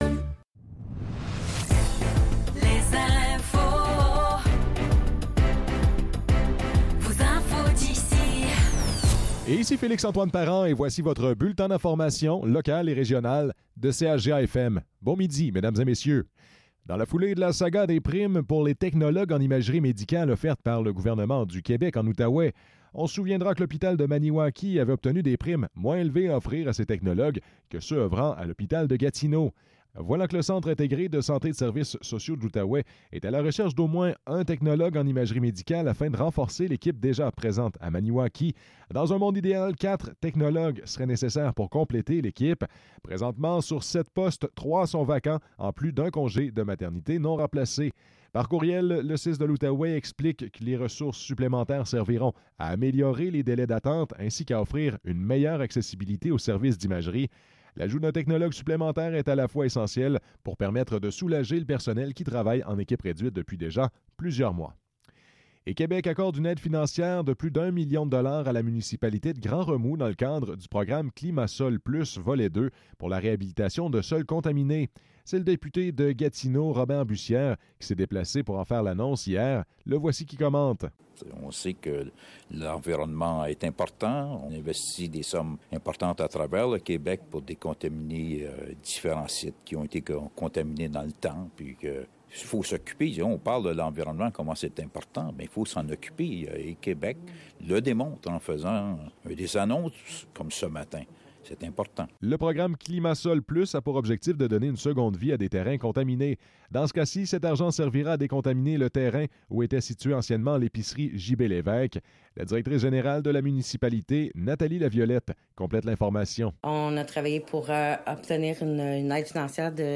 Nouvelles locales - 1 août 2024 - 12 h